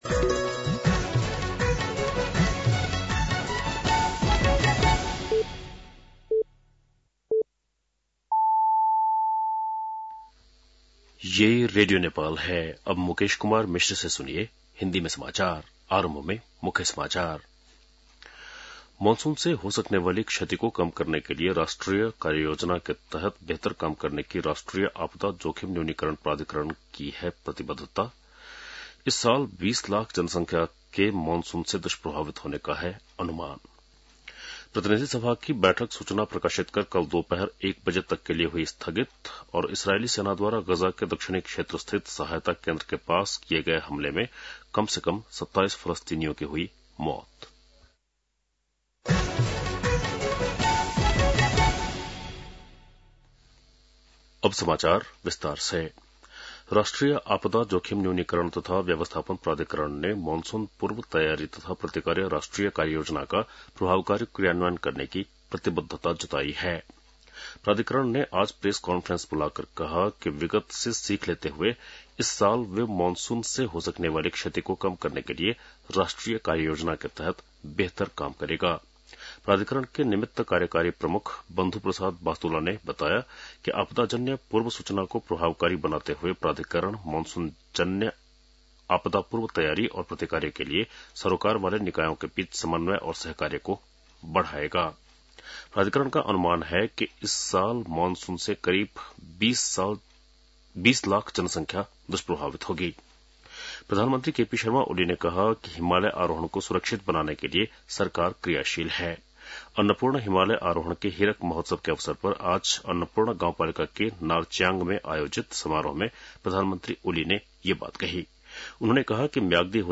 An online outlet of Nepal's national radio broadcaster
बेलुकी १० बजेको हिन्दी समाचार : २० जेठ , २०८२
10-pm-hindi-news-2-20.mp3